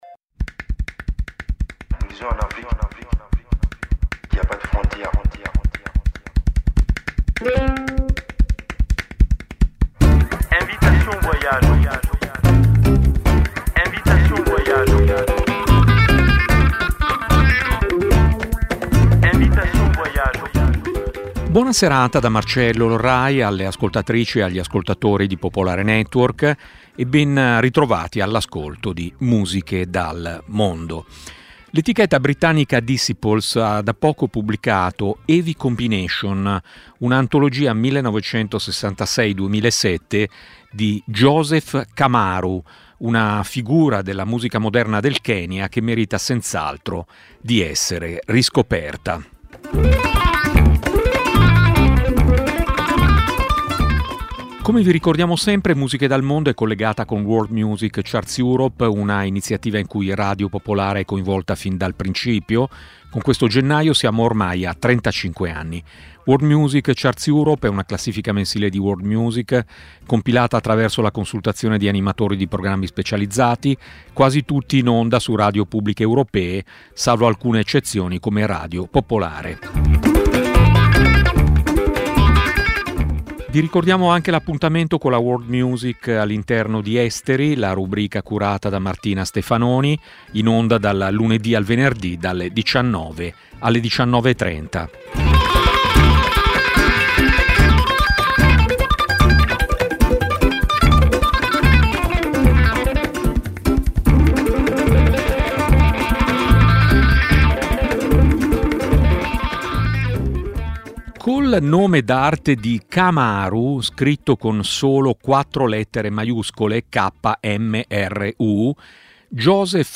Musiche dal mondo è una trasmissione di Radio Popolare dedicata alla world music, nata ben prima che l'espressione diventasse internazionale.
Un'ampia varietà musicale, dalle fanfare macedoni al canto siberiano, promuovendo la biodiversità musicale.